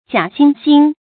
假惺惺 注音： ㄐㄧㄚˇ ㄒㄧㄥ ㄒㄧㄥ 讀音讀法： 意思解釋： 假心假意的樣子。